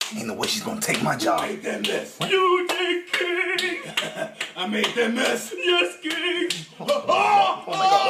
yes king who did that mess Meme Sound Effect
Category: Meme Soundboard